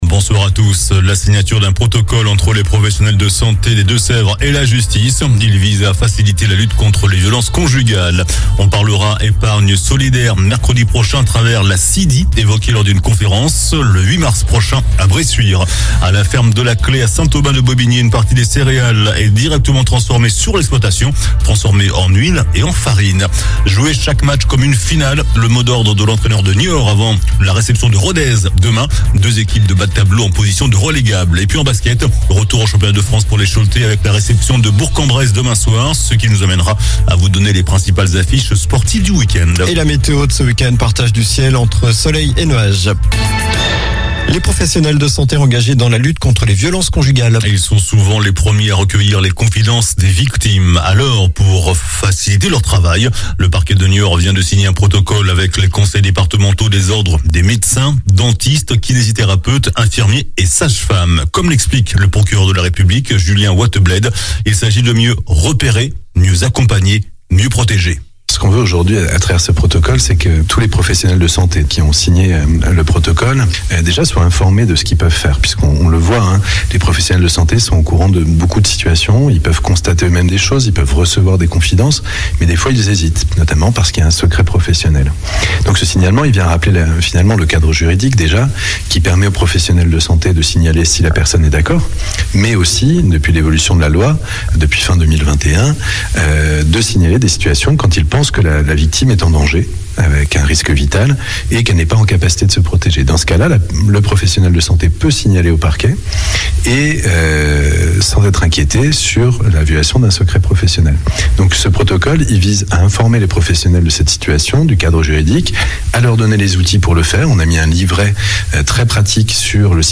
JOURNAL DU VENDREDI 03 MARS ( SOIR )